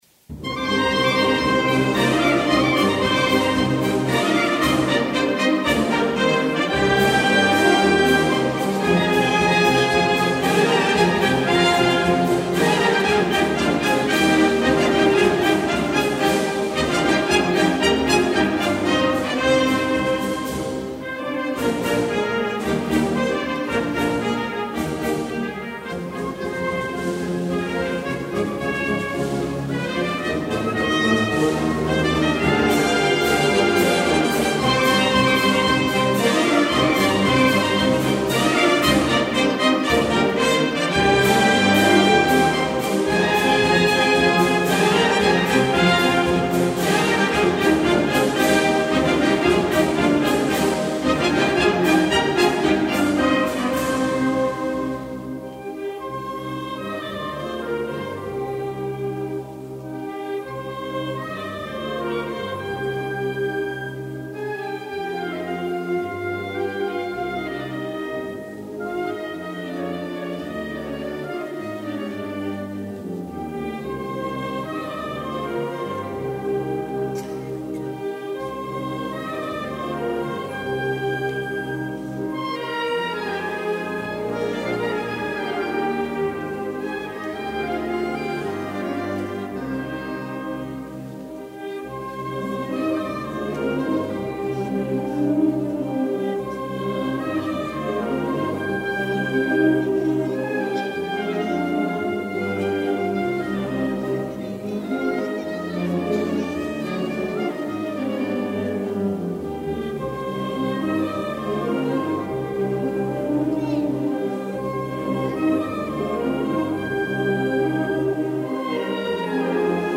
Marcia Sinfonica